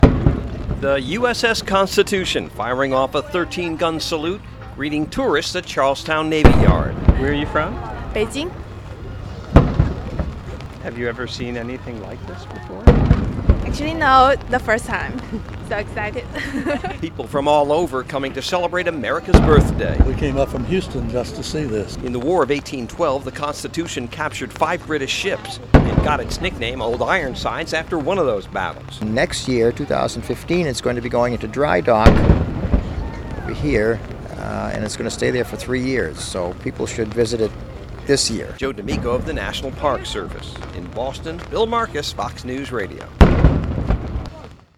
(CHARLESTOWN NAVY YARD, SOUTH BOSTON) JULY 4 – TOURISTS IN BOSTON BRAVED THREATENING WEATHER TO CELEBRATE JULY 4TH BY WELCOMING THE THREE-MASTED HEAVY FRIGATE THE U-S-S CONSTITUTION.